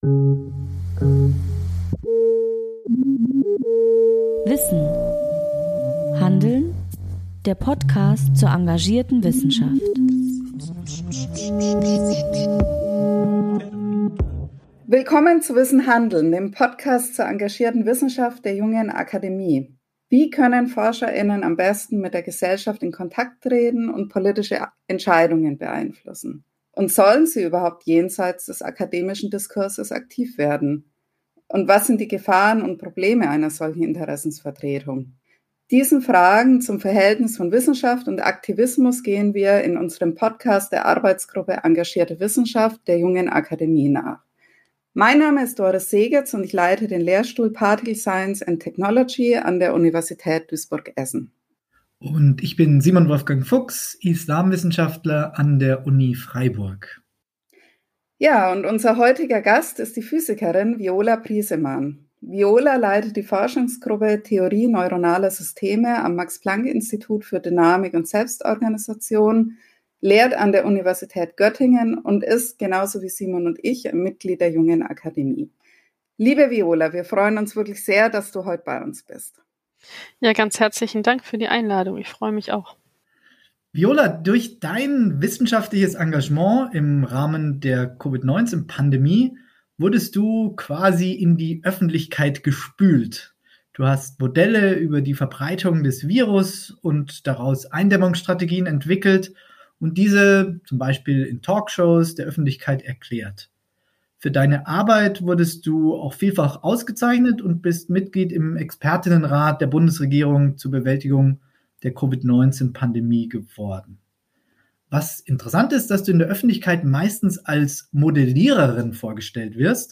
Viola Priesemann gibt Einblicke in ihre Begeisterung für komplexe Systeme und ihre Sehnsucht nach einheitlichen Daten. Das Gespräch dreht sich auch um die stete Suche nach dem wissenschaftlichen Konsens, mangelndes Vertrauen bei Runden der Ministerpräsident\*innen und darum, welche Freiräume zum radikalen wissenschaftlichen Umsteuern eine auskömmliche Grundfinanzierung ermöglicht.